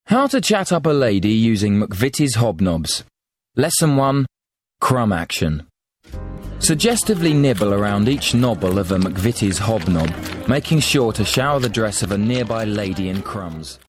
40's London, Friendly/Upbeat/Experienced
Commercial Showreel